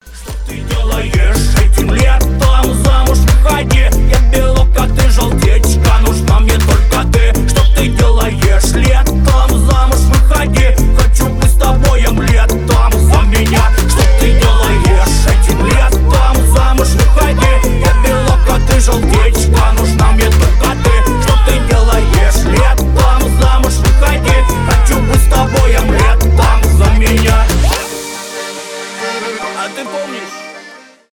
веселые , танцевальные